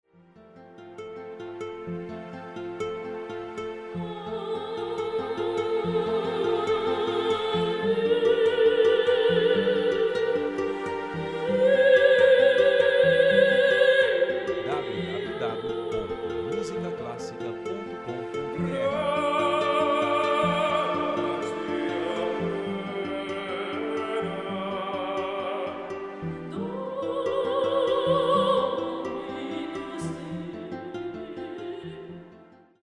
músicas para casamentos